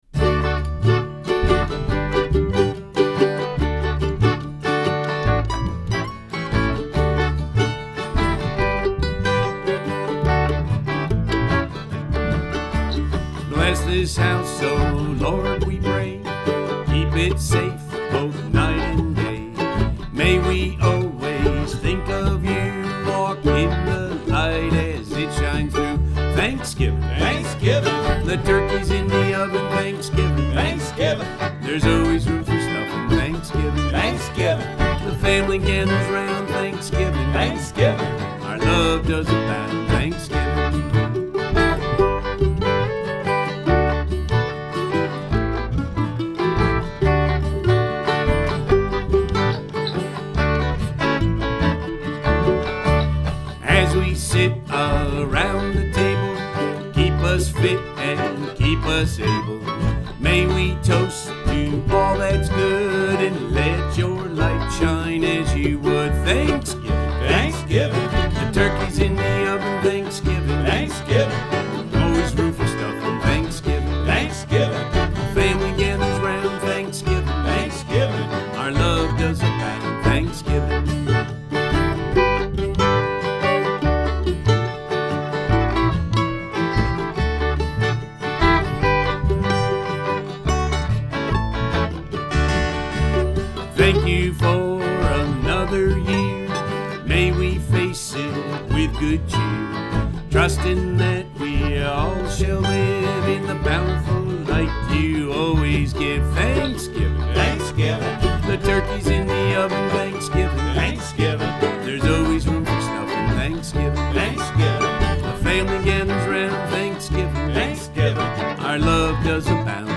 Recorded, mixed, mastered and inspired in Michigan.